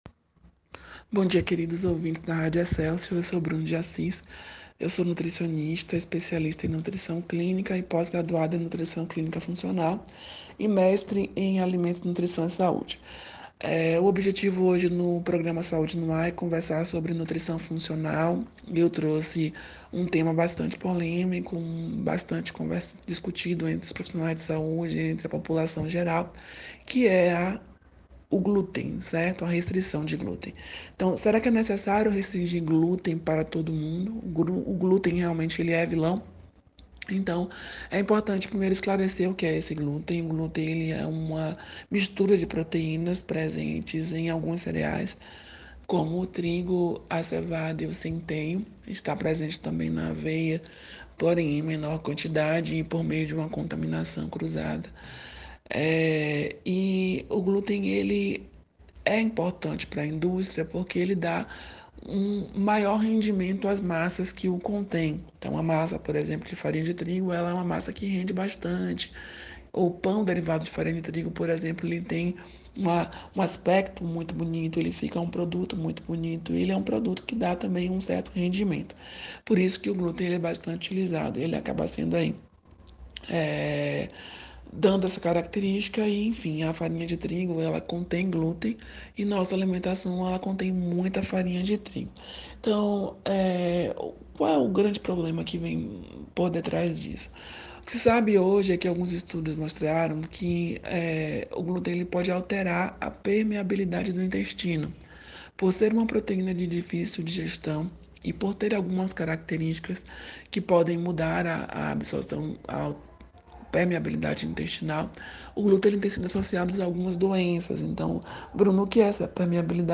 veiculado às segundas-feiras no Programa Saúde no ar, pela Rádio Excelsior da Bahia, AM 840